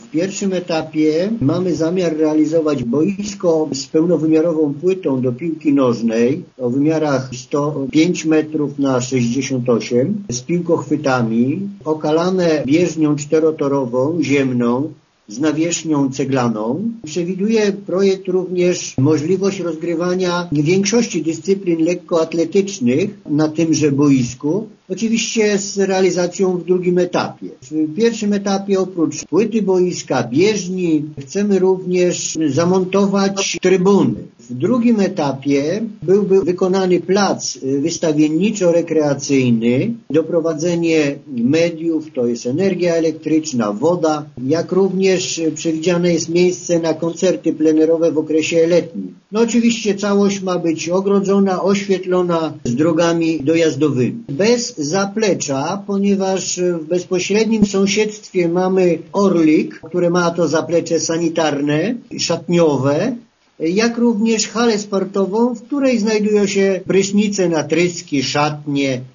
Cała inwestycja, której efektem będzie powstanie Centrum Organizacji Imprez Sportowych i Społeczno-Kulturalnych, będzie realizowana w dwóch etapach – informuje wójt Franciszek Kwiecień: